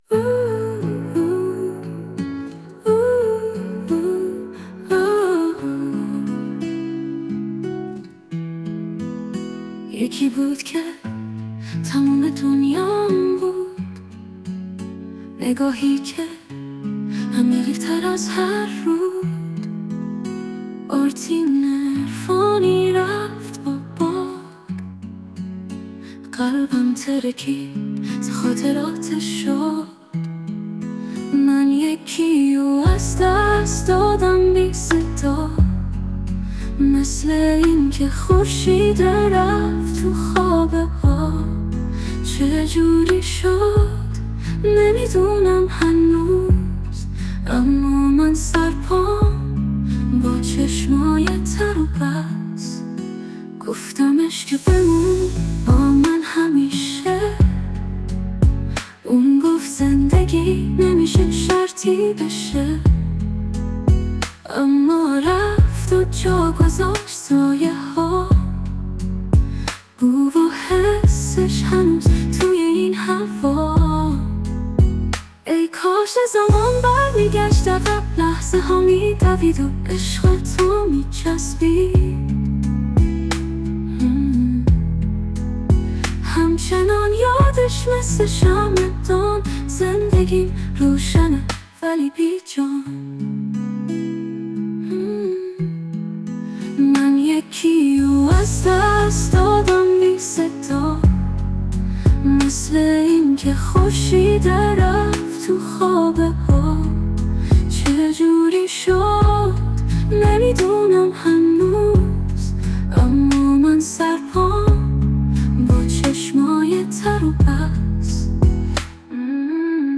یک صدای عالی و با کفیت بالا
صدای قشنگی داشت
نصف آهنگ بیشتر کلمات مشخص و واضح نبود ولی برای اولین کار جالب بود, می تونه پیشرفت کنه , بهتره اگه قراره حرفه ایی تر کار کنه به از اسم مستعار و بدون عکس و ... استفاده کنه و کار کنه به دلیل محدودیت های خوانندگی بانوان در ایران.
یه جاهایی بیت خیلی همگام نبود ولی در کل ژالب بود :)